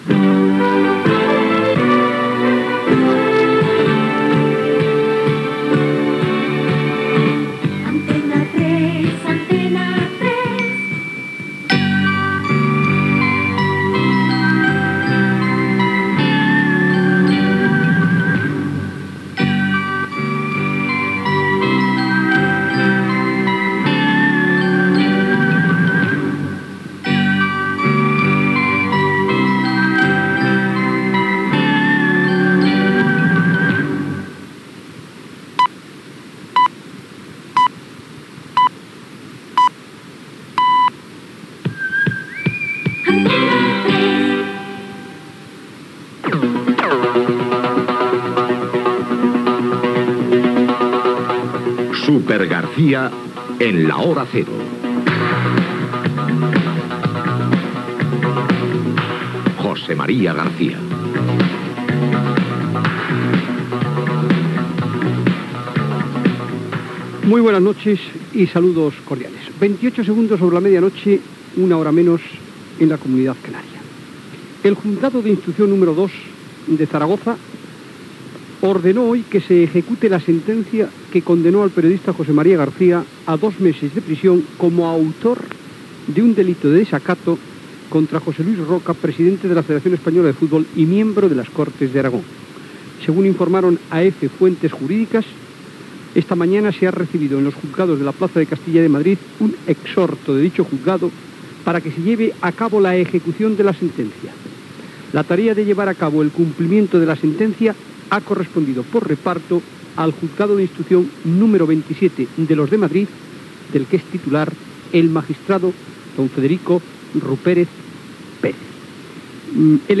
Indicatiu de l'emissora, careta del programa, lectura de l'ordre de compliment de la sentència que condemna a José María García a presó, després que el Tribunal Constitucional espanyol rebutgés el seu recurs i comentari seu
Esportiu
FM